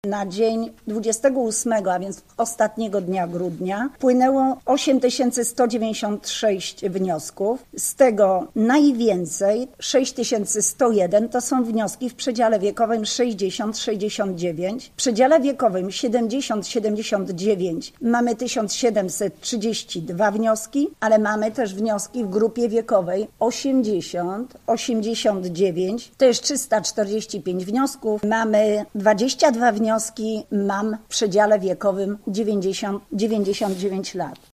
Ponad 80 wniosków zaledwie jednego dnia złożono w gorzowskim oddziale ZUS o świadczenie z programu „Mama 4+”. W sumie w całym kraju tych wniosków jest już 8 tysięcy. Mówi Minister Rodziny, Pracy i Polityki Społecznej – Elżbieta Rafalska: